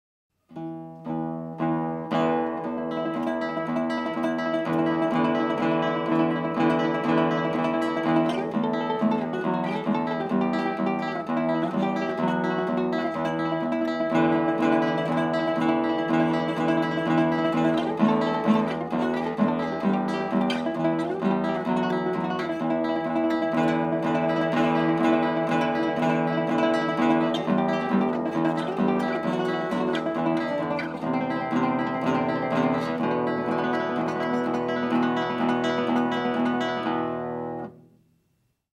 Title: A escrita idiomática para guitarra